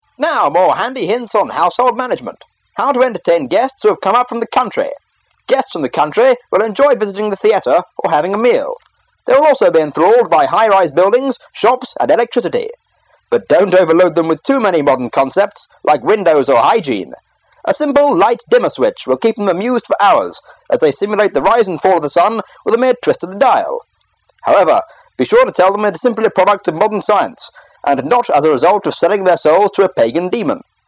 Voice Over